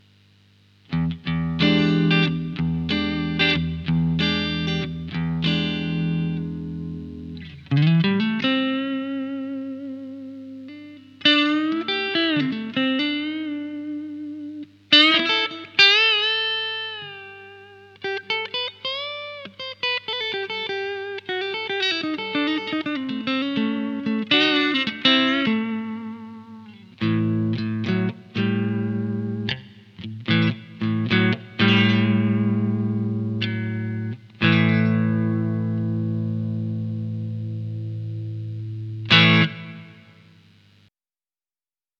Bon allez, pour terminer en beauté, voici les samples en clean.
Le preamp 1 est le JMP-1 modifié
ComparatifPreamp-Clean-Preamp1.mp3